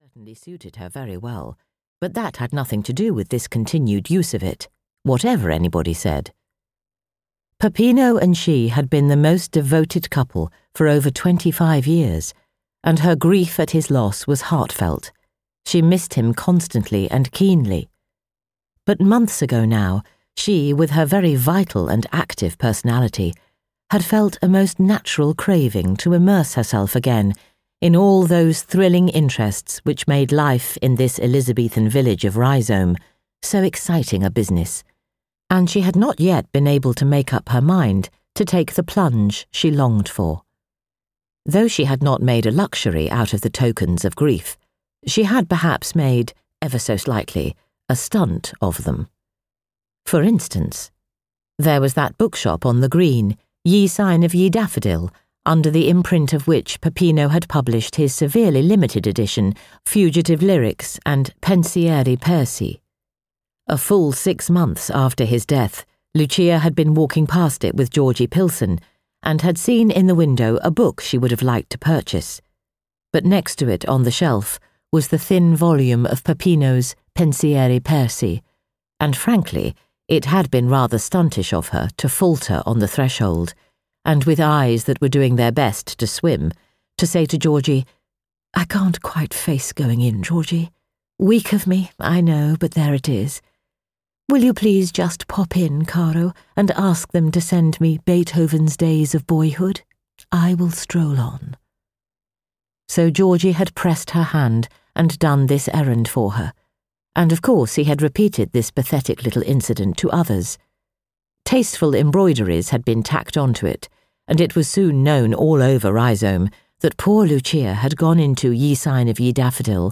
Audio knihaThe Complete Mapp and Lucia, Volume 2 (EN)
Ukázka z knihy